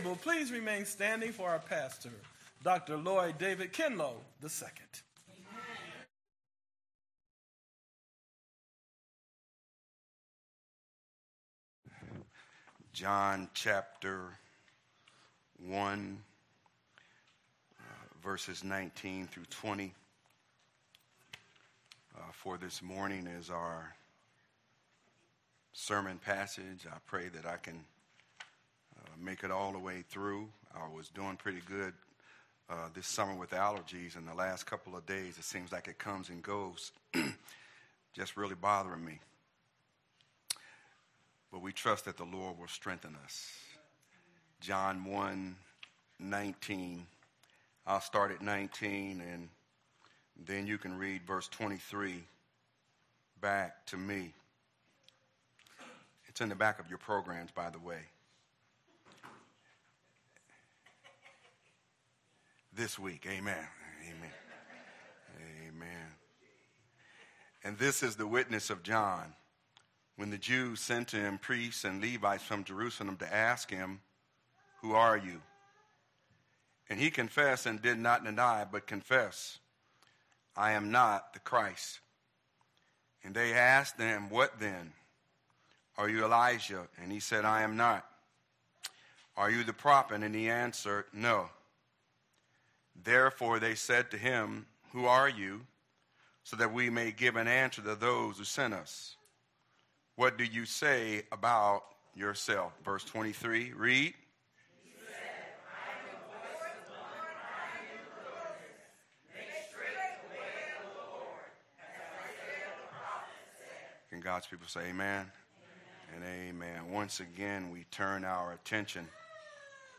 Sermons | The Word of God Community Church